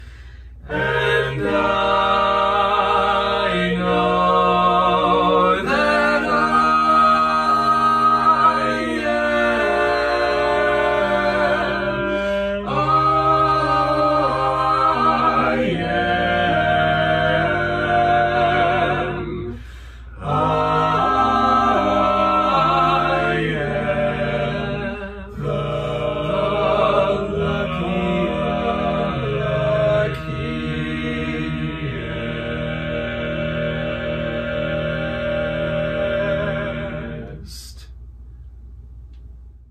Key written in: E Major
How many parts: 4
Type: Barbershop
All Parts mix:
Learning tracks sung by